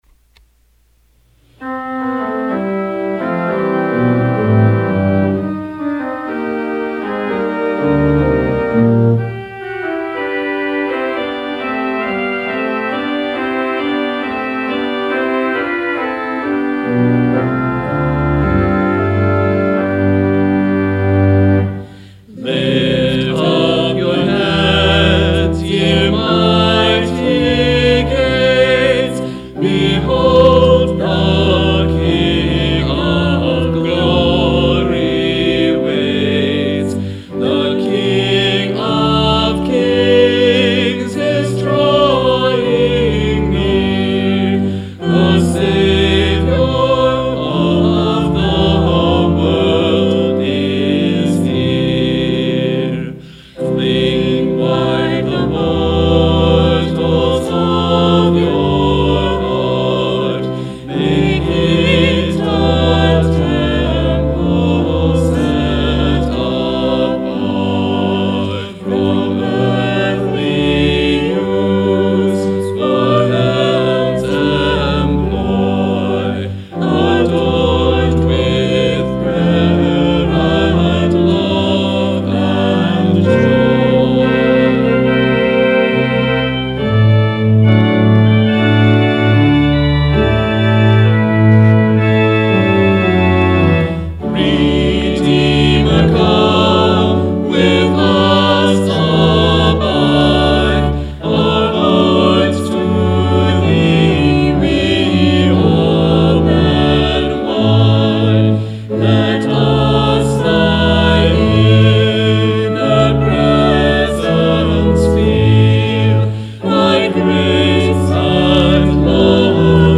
Hymn: Lift Up Your Heads You Mighty Gates
Sermon: Mapping the Road toward Hope